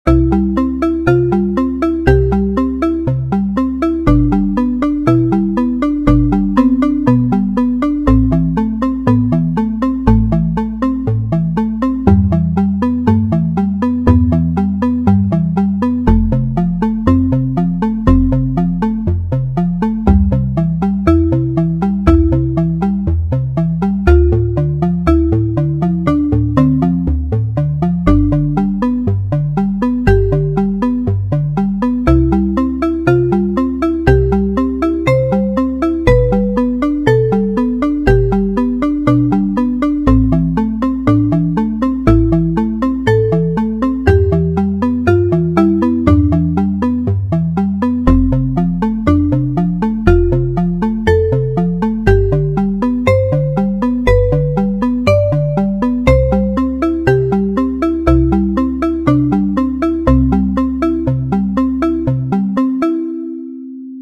ポジティブな感じ。ループ対応。
BPM60